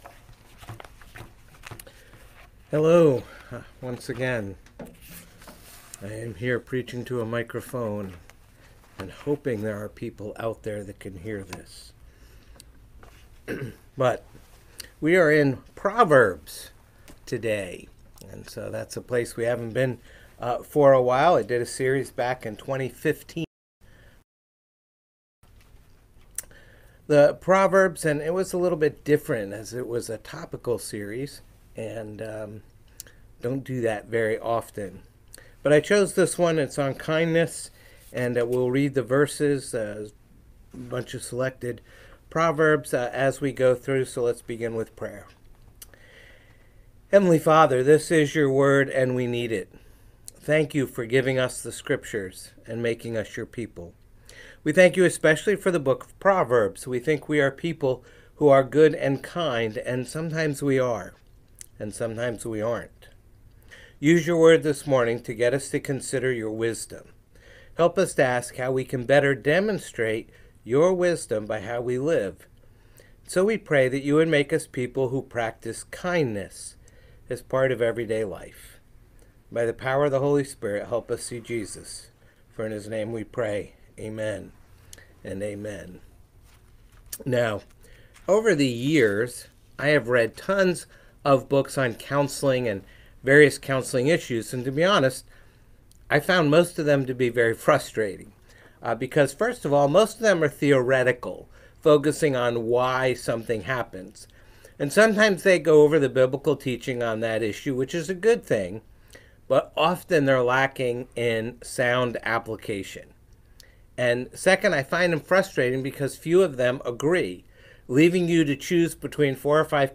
Potomac Hills Presbyterian Church Sermons
phpc-worship-service-1-25-26.mp3